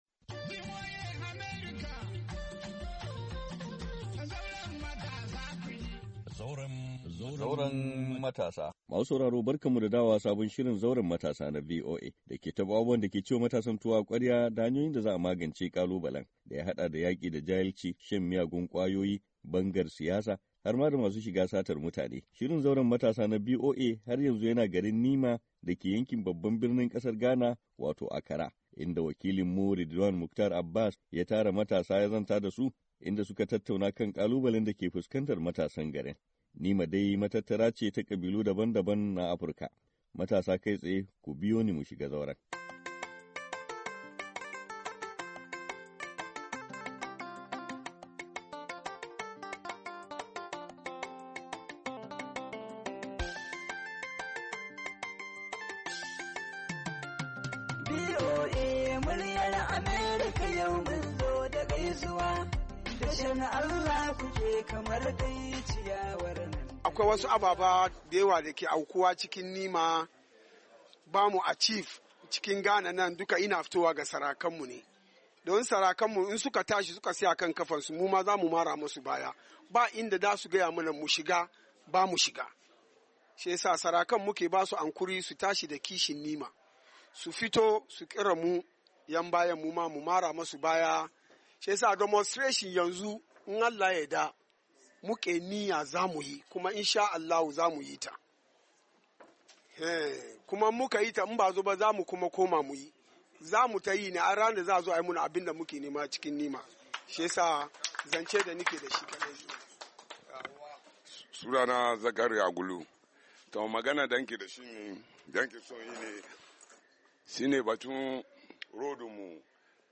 ZAUREN MATASA: Tattaunawa Da Matasan Unguwar Nima A Birnin Accra, Afrilu 17, 2023
Shiri na 110 na zauren matasa da ya zauna a garin Nima da ke yankin babban birnin kasar Ghana wato Accra, inda matasa su ka taru don baiyana abubuwan da ke ci mu su tuwo a kwarya da su ka shafi garin na Nima.